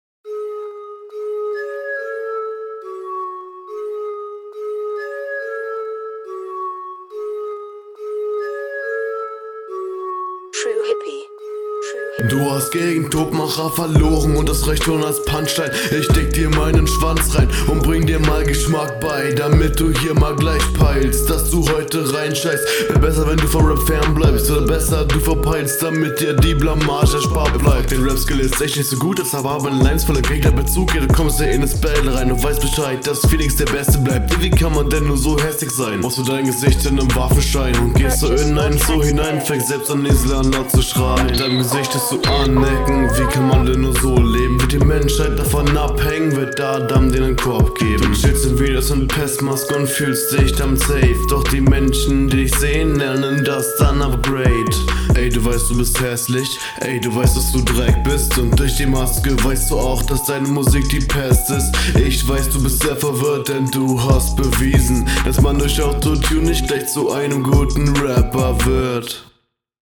Flow: Jo ziemlich abgehackt und teilweise n bissl off Text: Jo rap fronten, wenn man …
Flow: Der Einstieg war gut, danach klingt der Flow teilweise etwas unsicher, gegen Ende wieder …